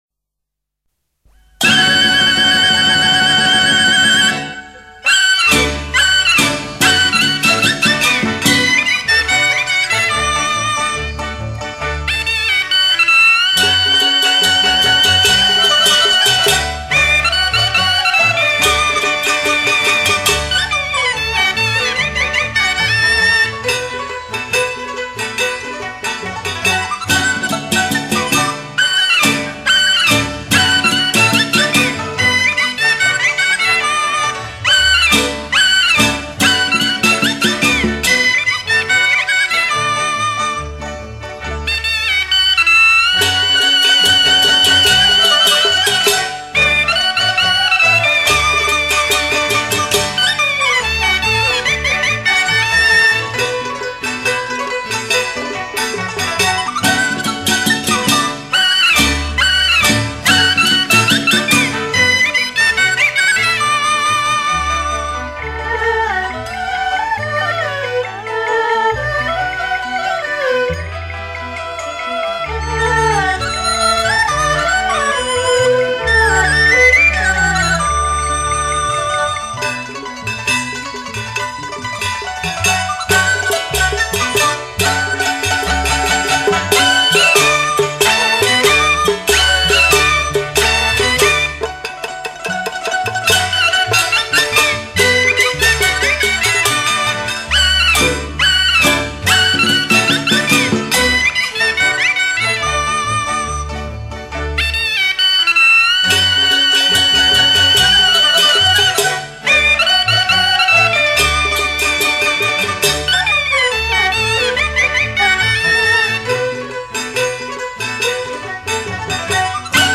合奏